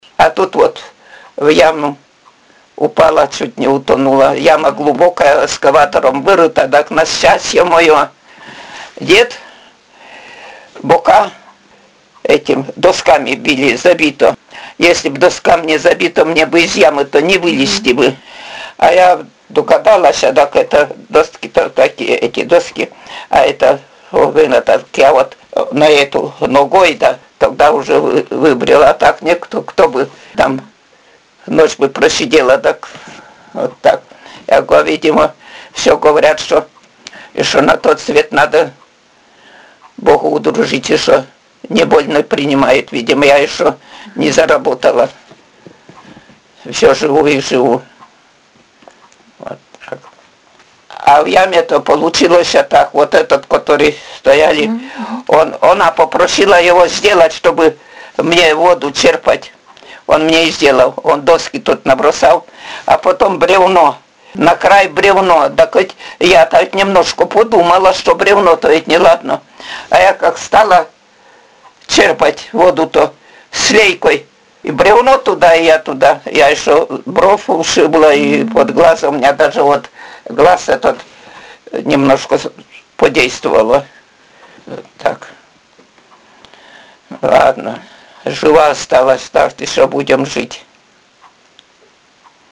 «А тут вот в яму упала, чуть не утонула…» — Говор северной деревни
Пол информанта: Жен.
Аудио- или видеозапись беседы: